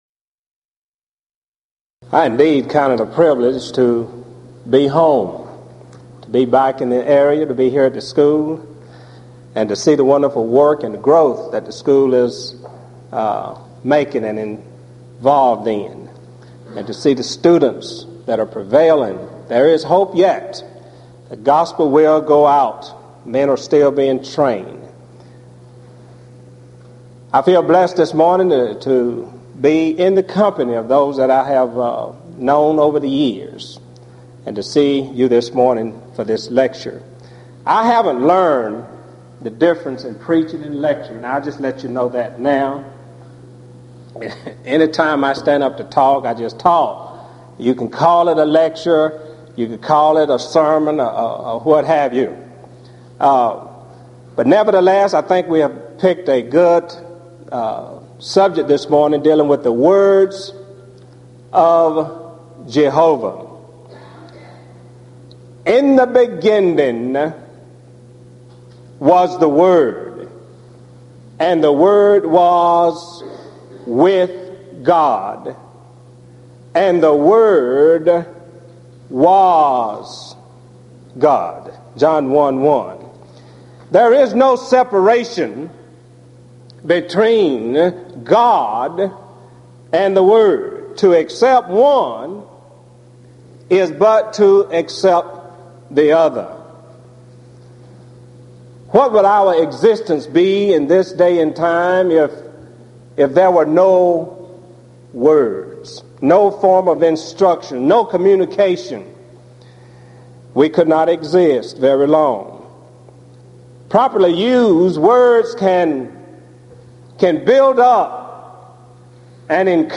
Event: 1997 East Tennessee School of Preaching Lectures Theme/Title: Studies In The Book of Exodus